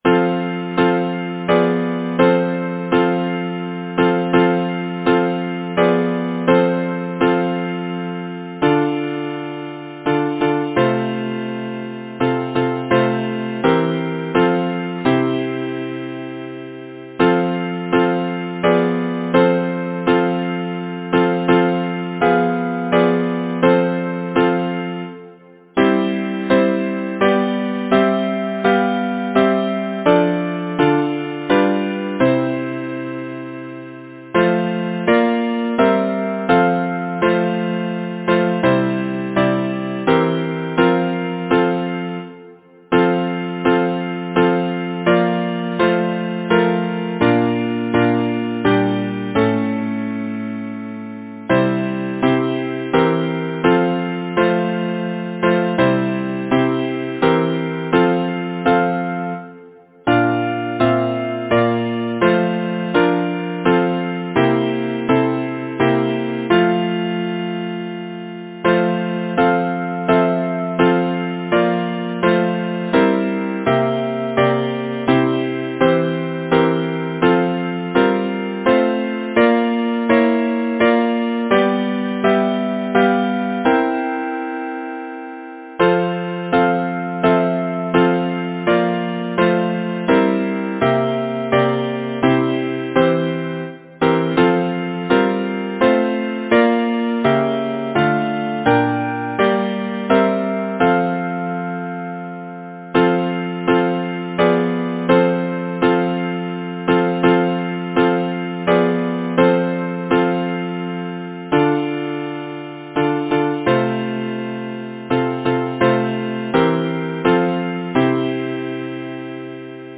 Title: Farewell to my harp Composer: Elizabeth Gluyas Philp Lyricist: Thomas Moore Number of voices: 4vv Voicing: SATB Genre: Secular, Partsong
Language: English Instruments: A cappella